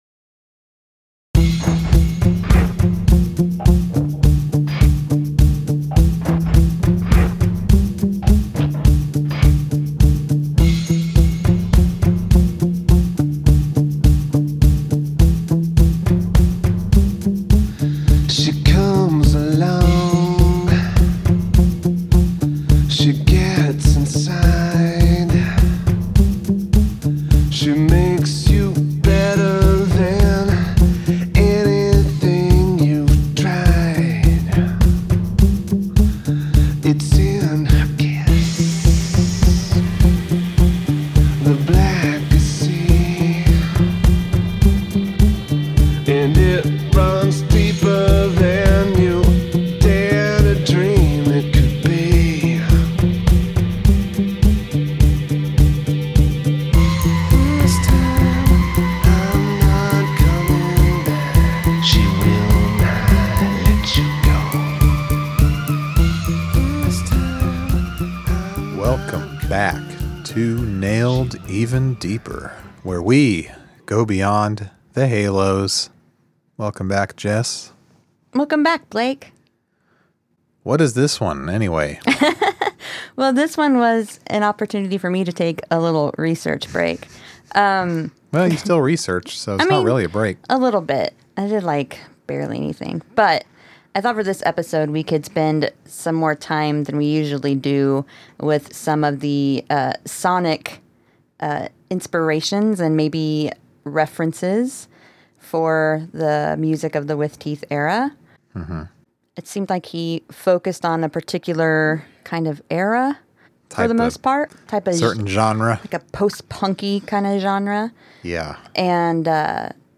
In this UNLOCKED episode, we attempt to crack the code of the SHADOW VOWEL. We also explore Reznor's overall musical influences in the With Teeth era--but we get especially deep into that certain affected style of singing. Contains lots of cool comparison clips!